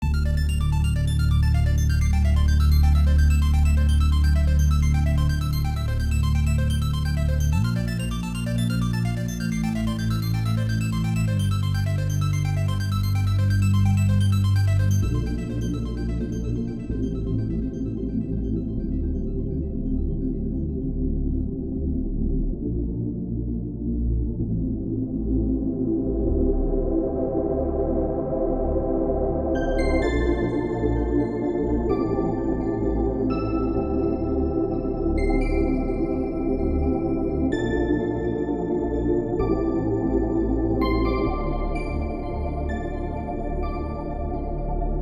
cloud rap sample_64bpm.wav